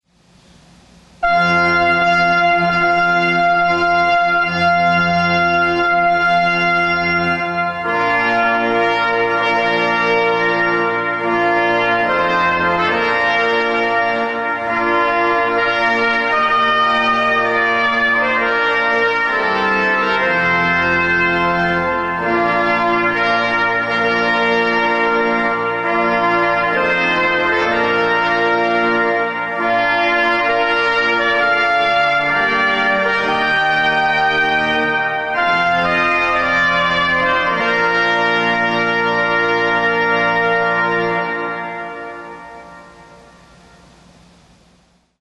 procession (mp3) sound majestic and stately.
Listen to audio of Arcadia Brass playing some of our most frequently requested wedding music...